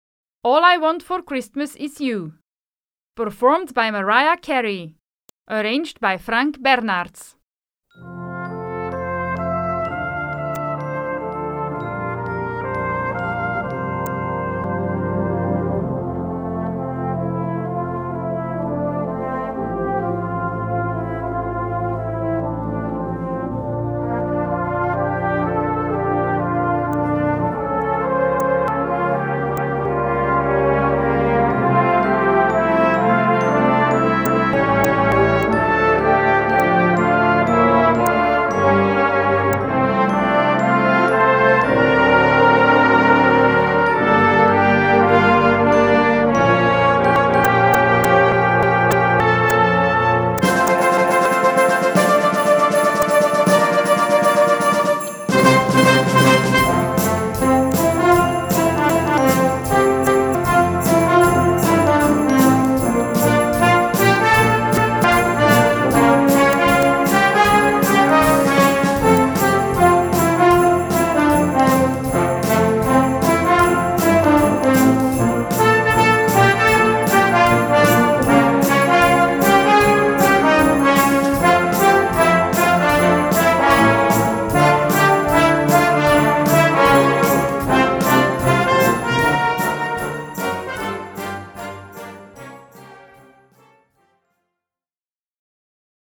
Gattung: Weihnachten
Besetzung: Blasorchester